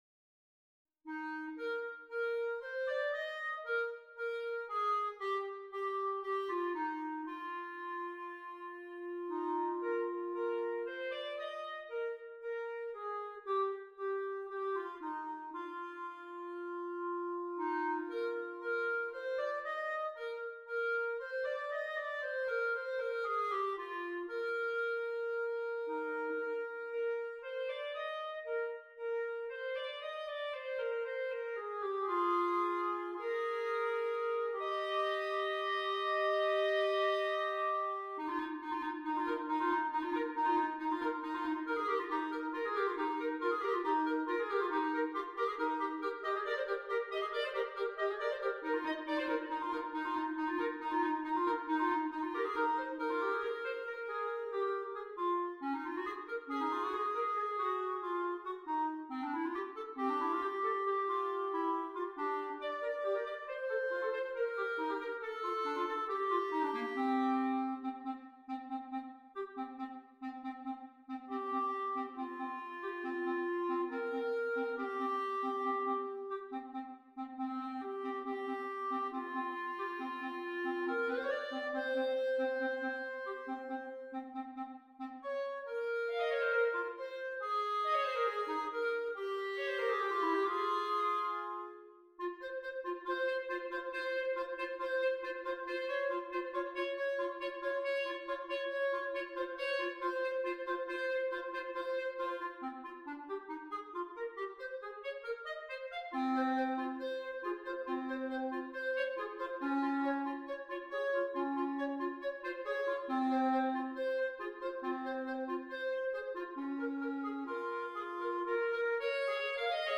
2 Clarinets